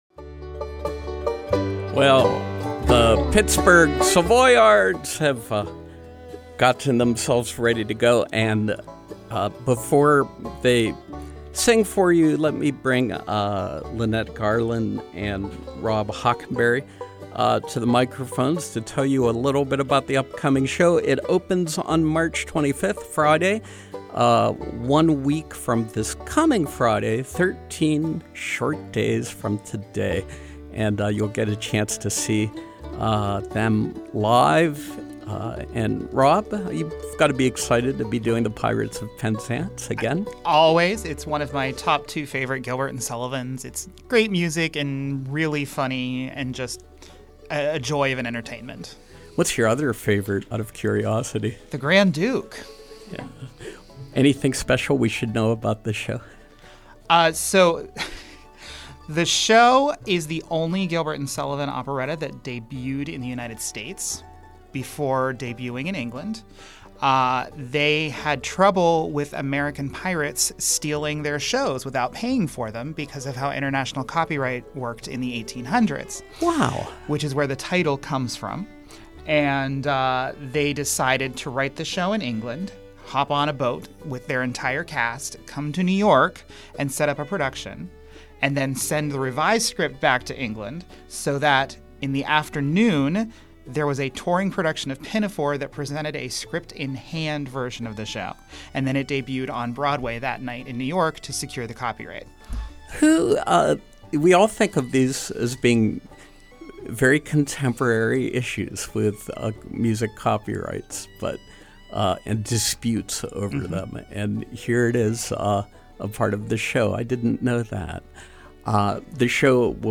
Live Performance: The Pirates of Penzance, Pittsburgh Savoyards